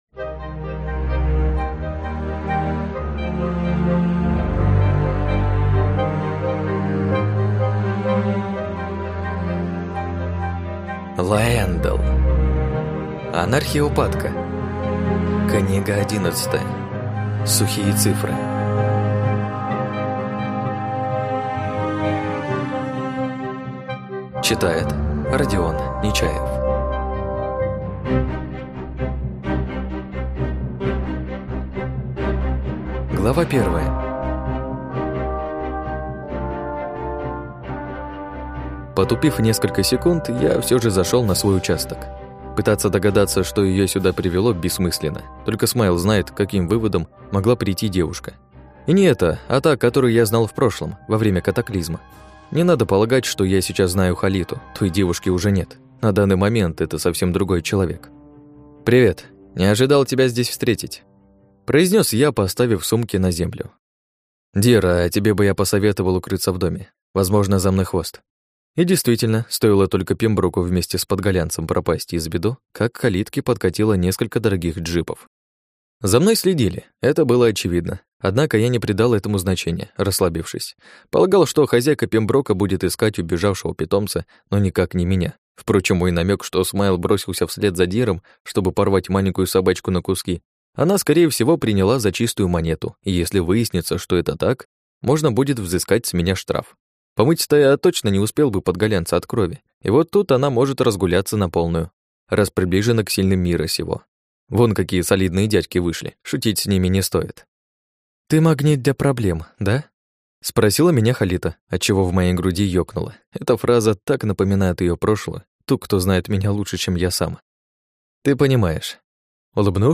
Аудиокнига Сухие цифры | Библиотека аудиокниг